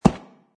grass.mp3